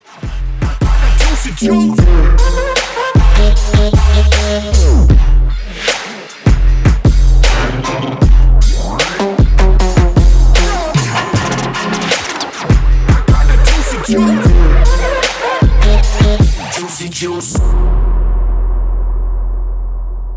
Elektronisk musik, Android